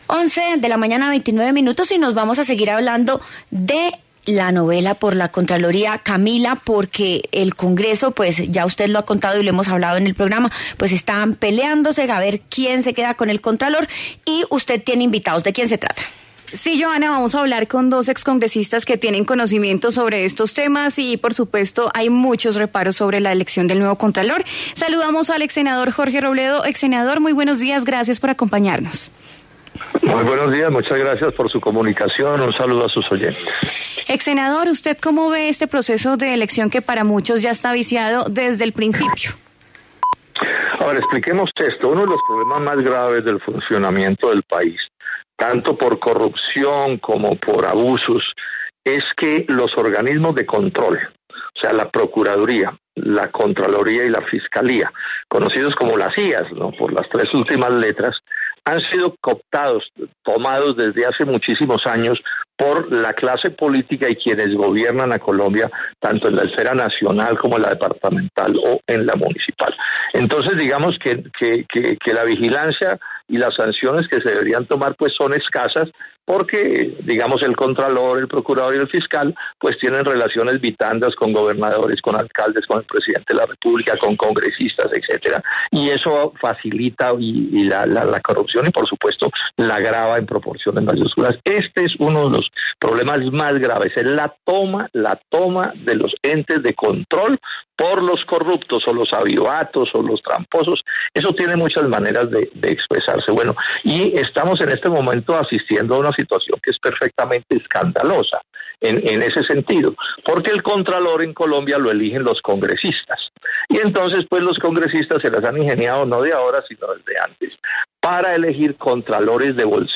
Los exsenadores Jorge Robledo y Rodrigo Lara resaltaron la necesidad de una reforma al proceso de selección por parte del Congreso.